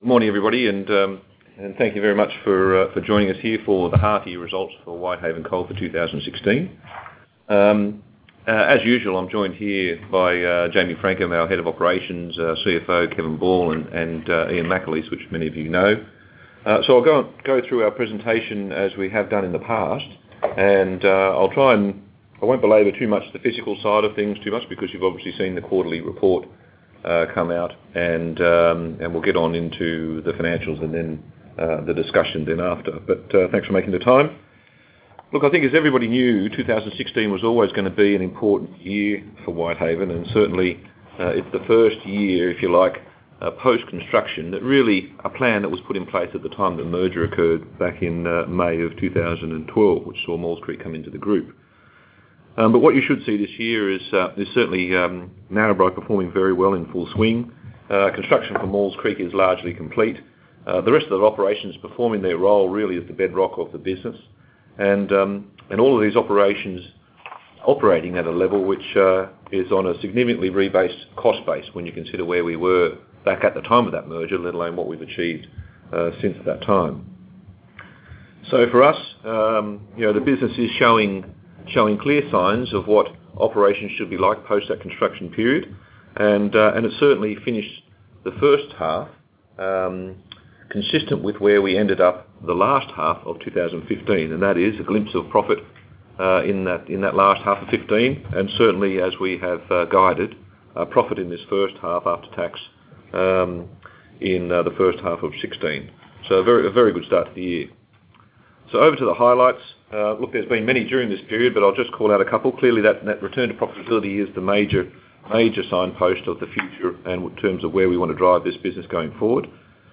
Half-Year-results-Conference-Call-5-Feb-16.mp3